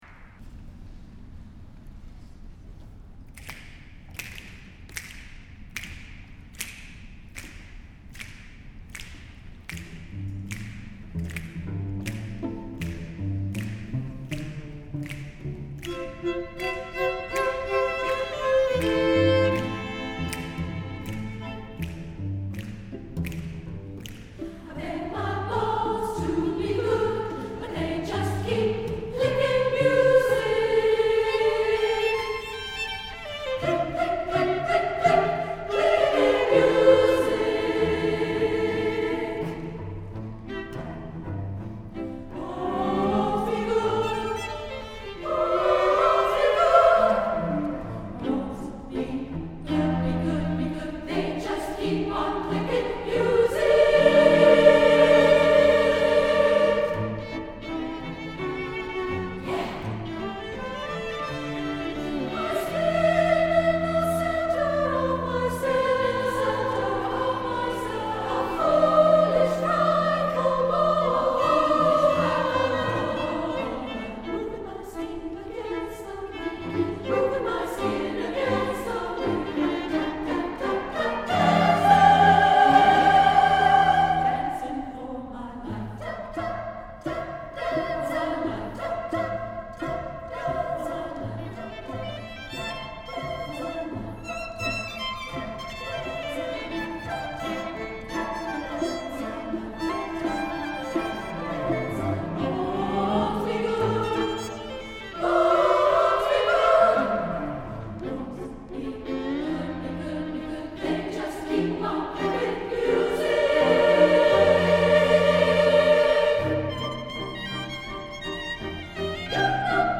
SSA with strings